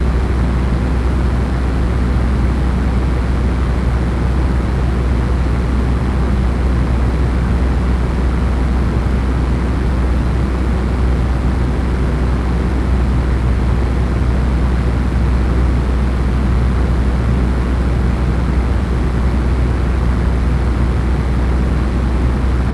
Propeller.wav